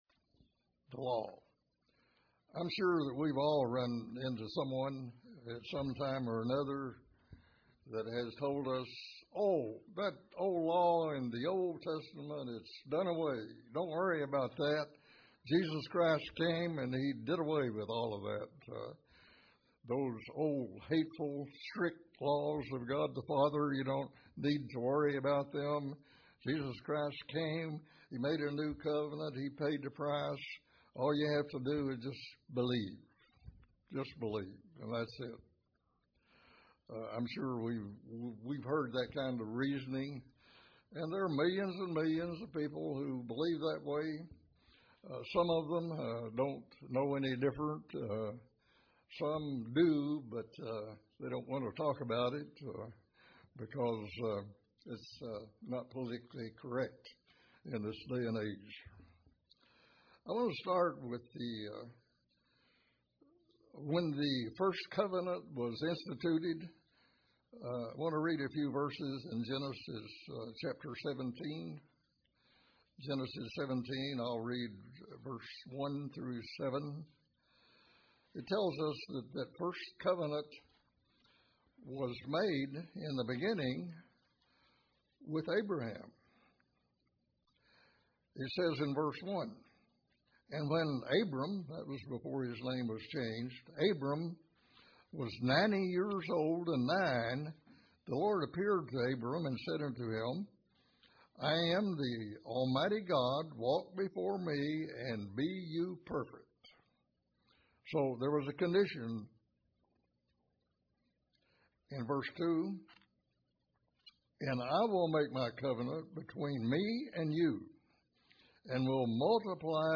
Just what was nailed to the cross? These questions are answered in this sermon.
Given in Paintsville, KY